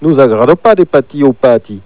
Le i final de segui est acentu�.